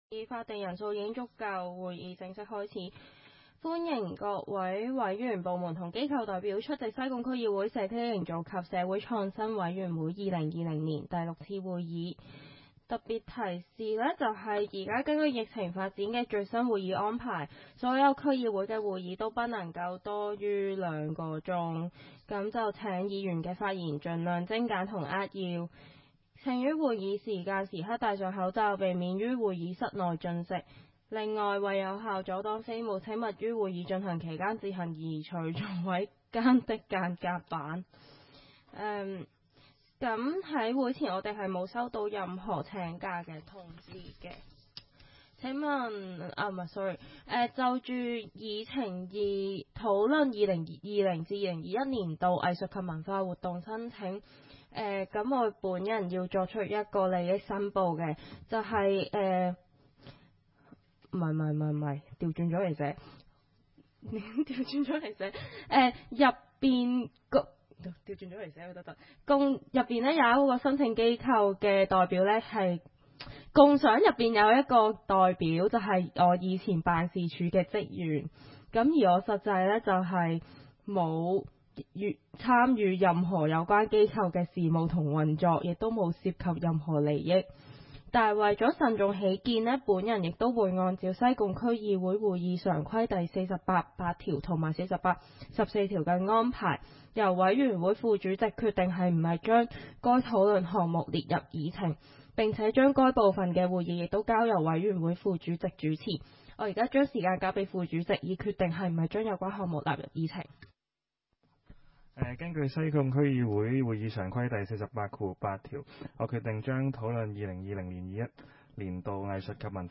委员会会议的录音记录
地点: 将军澳坑口培成路 38 号 西贡将军澳政府综合大楼 三楼西贡区议会会议室